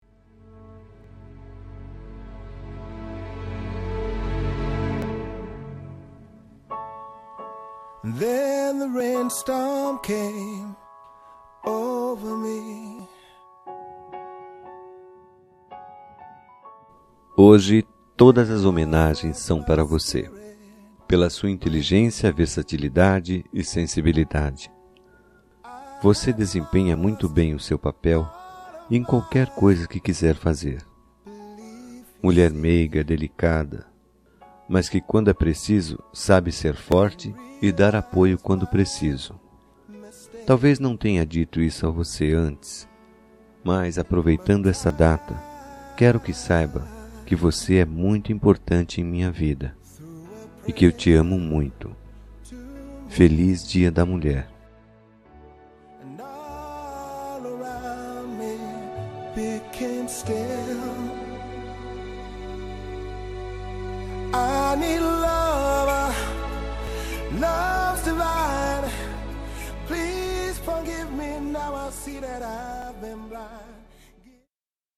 Homenagem aos Noivos – Voz Feminina – Cód: 3080